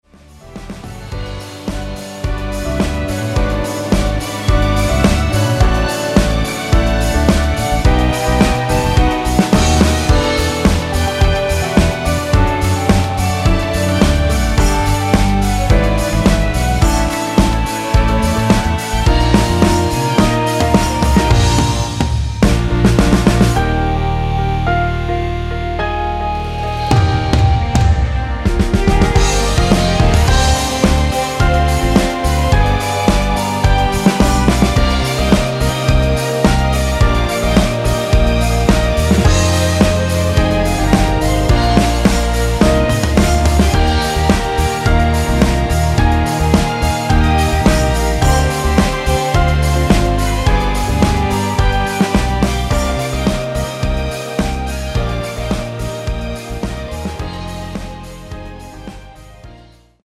전주 없이 시작 하는곡이라 노래 하시기 좋게 2마디 전주 만들어 놓았습니다.(약 5초쯤 노래 시작)
앞부분30초, 뒷부분30초씩 편집해서 올려 드리고 있습니다.